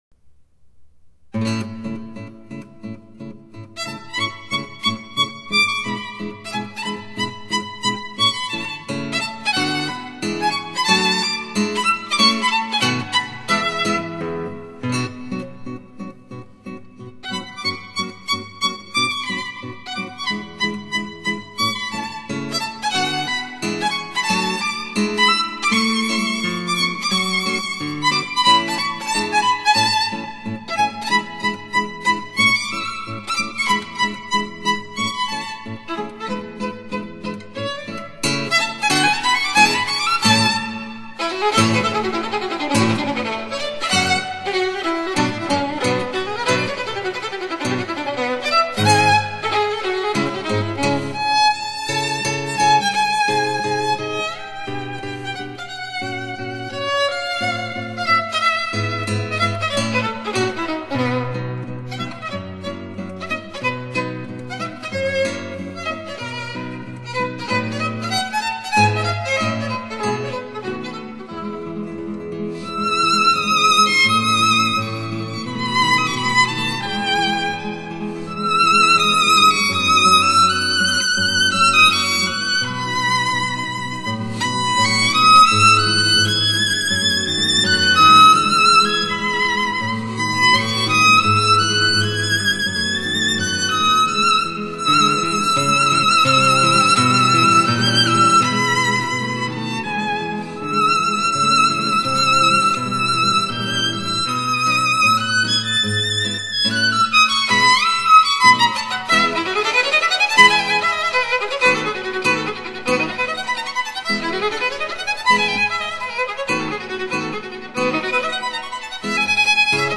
虽然吉他的动态有所收敛，但并不会因为小提琴的出色演奏而让你忽视它的存在。